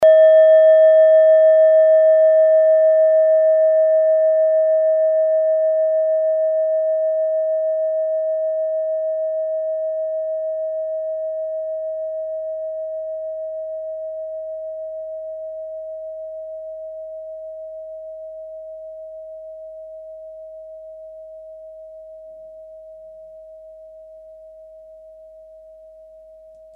Klangschalen-Typ: Bengalen
Klangschale Nr.7
Gewicht = 310g
Durchmesser = 10,4cm
(Aufgenommen mit dem Filzklöppel/Gummischlegel)
klangschale-set-1-7.mp3